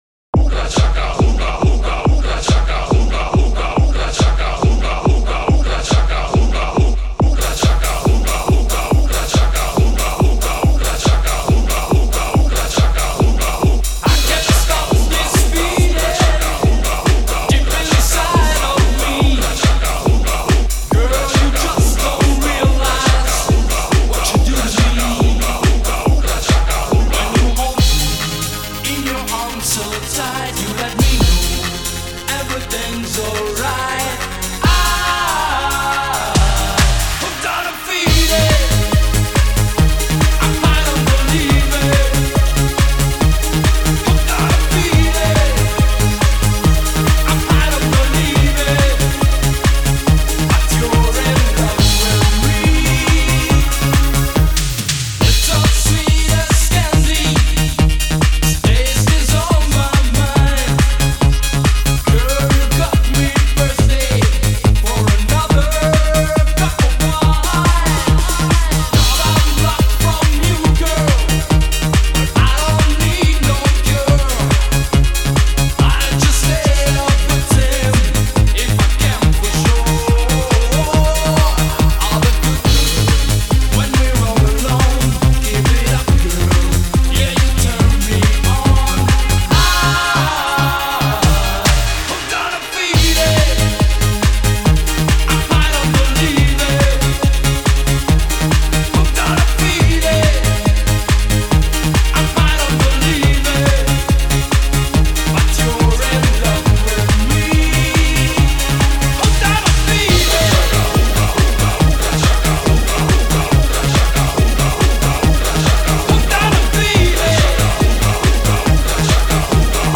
Genre: Euro-House.